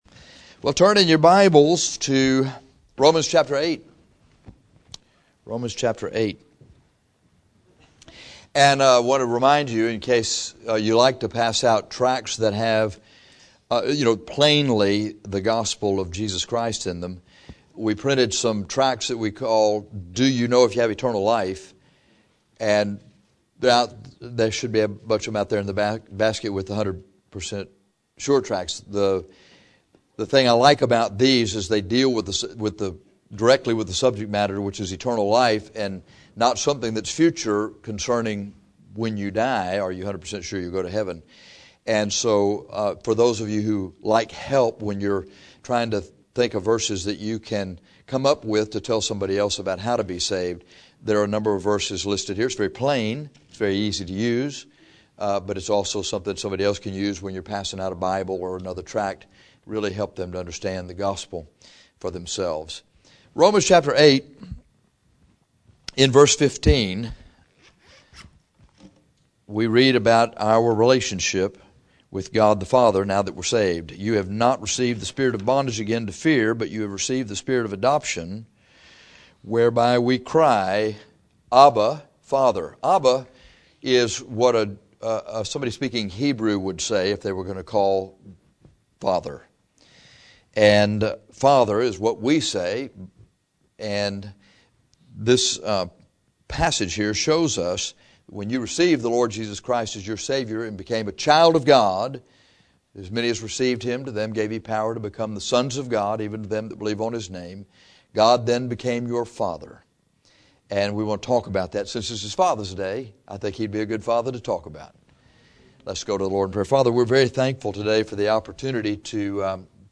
Abba Father Rom 8:15 - Bible Believers Baptist Church | Corpus Christi, Texas
So, rather than preach to our dads this Father’s day, we are going to preach about God, our Heavenly Father, Abba Father, and his relation to us as his children.